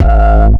Index of /m8-backup/M8/Samples/breaks/breakcore/earthquake kicks 1
boom kick.wav